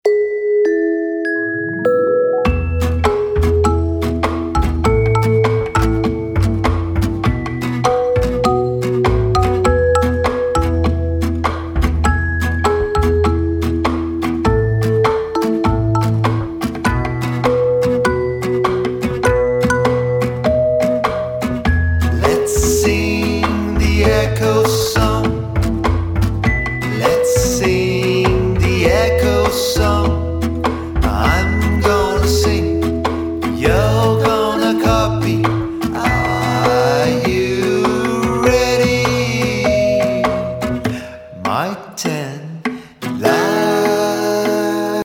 (vocalization)